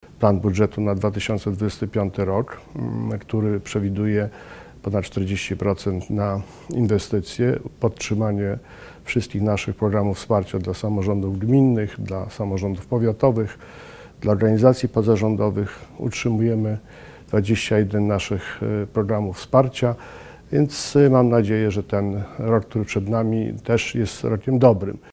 Marszałek Mazowsza Adam Struzik mówi, że jeżeli chodzi o ten rok, to również będzie on pełen inwestycji i wsparcia samorządów.